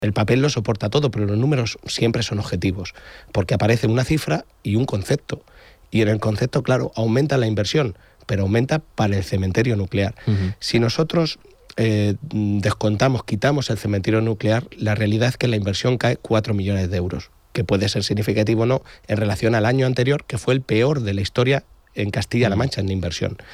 En una entrevista en Onda Cero Castilla-La Mancha
Cortes de audio de la rueda de prensa